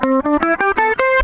Le guitariste électrique - La pentatonique majeure
Une gamme pentatonique majeure se compose de 5 notes.
Gamme Pentatonique Majeure de Do (cliquez pour écouter)